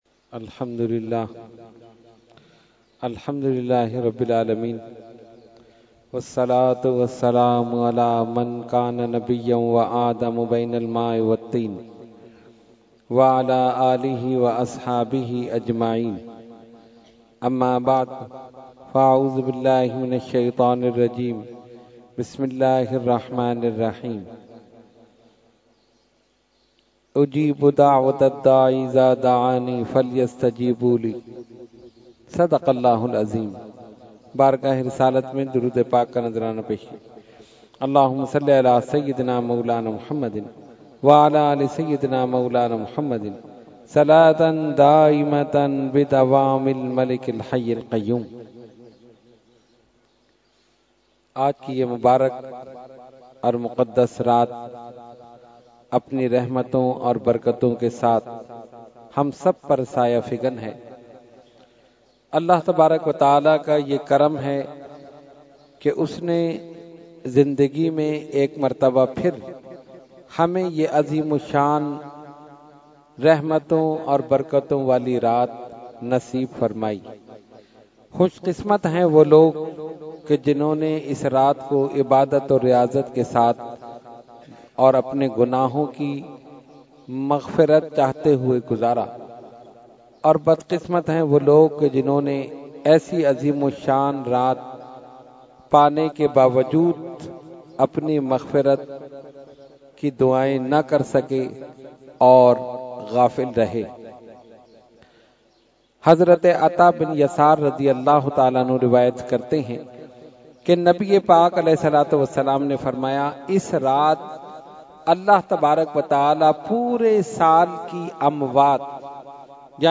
Category : Speech | Language : UrduEvent : Shab e Baraat 2019